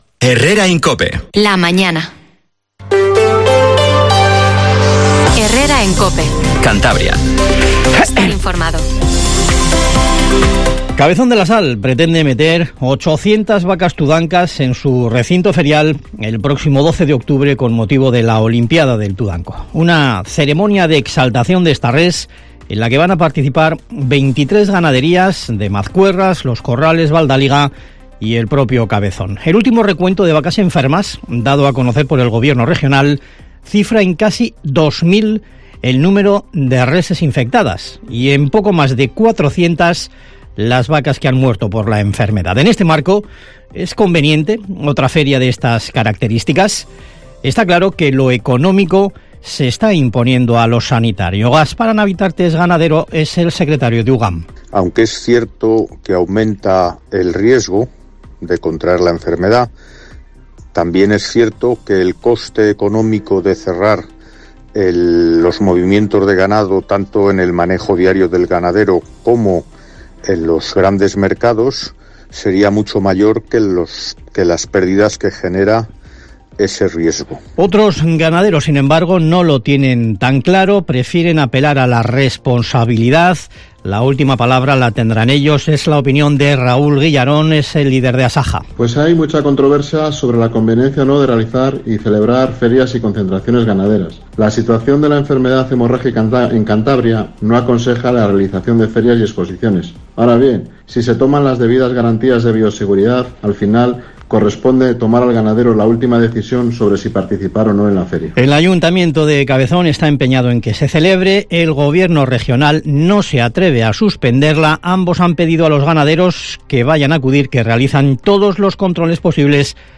Informativo HERRERA en COPE CANTABRIA 07:20